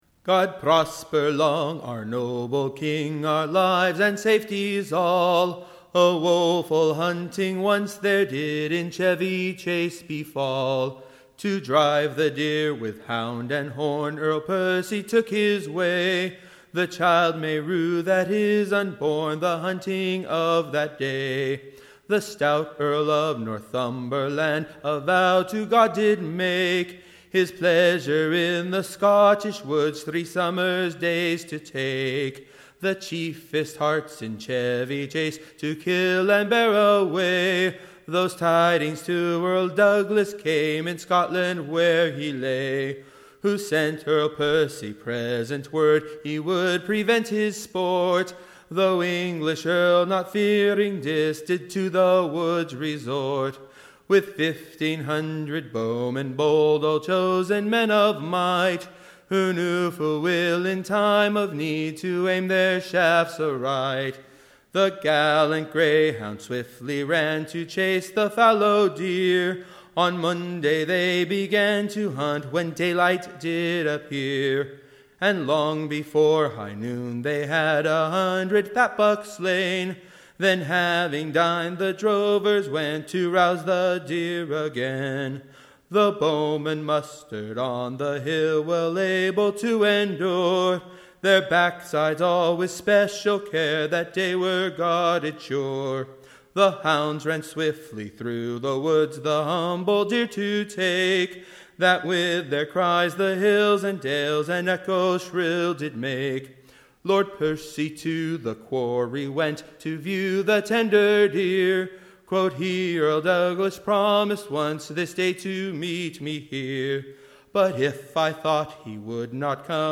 EBBA 36163 - UCSB English Broadside Ballad Archive